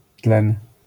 wymowa:
IPA[tlɛ̃n], AS[tlẽn], zjawiska fonetyczne: nazal.